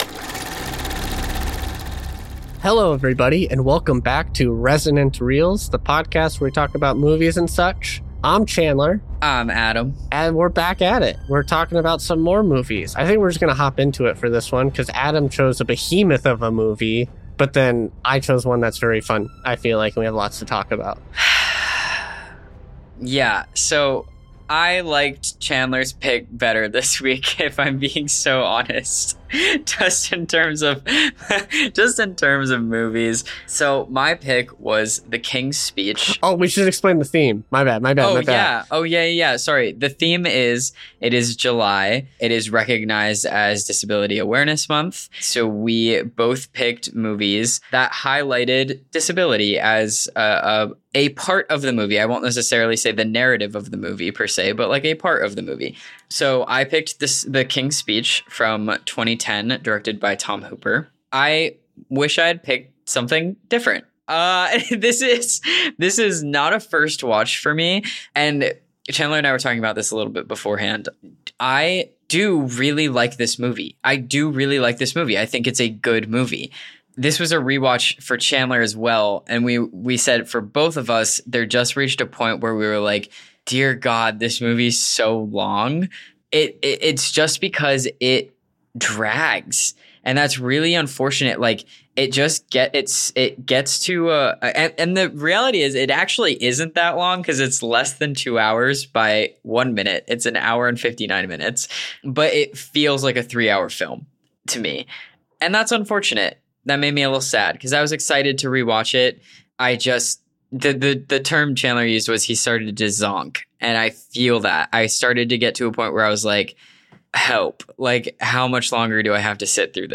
Welcome to Resonant Reels where two friends come together to review, discuss, and analyze their favorite movies.